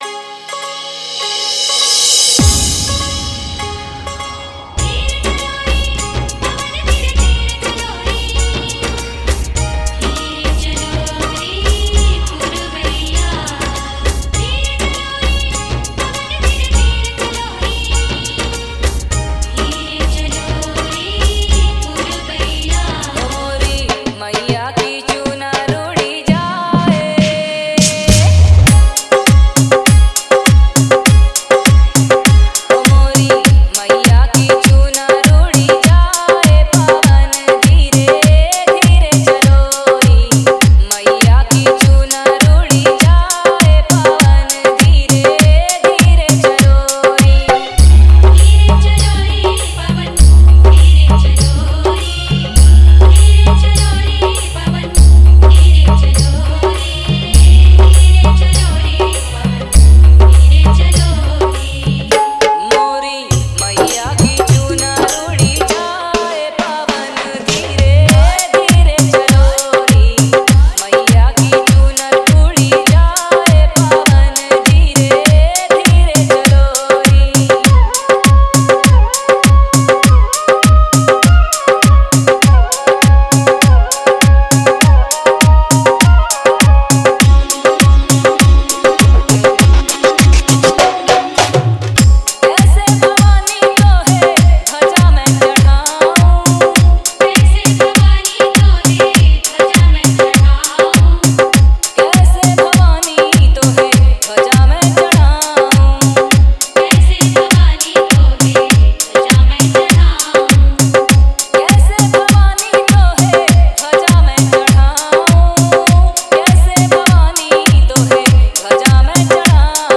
Navratri Song